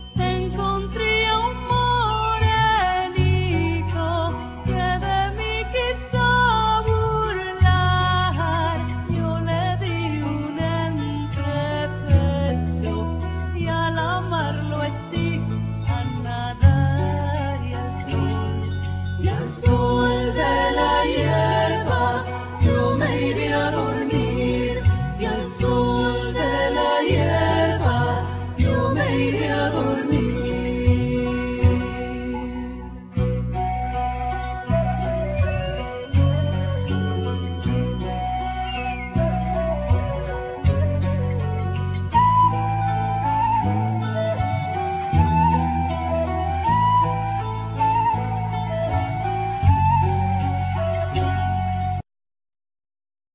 Vocals,Chorus,Keyboards
Gaita,Gallega,Gaida bulgara,Flute,
Bouzuki,Saz,Tamboril
Tabla,Zarb,D7rbouka,Pandero,Djambe,Effects
Zanfona,Riq
Accordion
Clarinet,Bass clarinet
Saxophone,Flute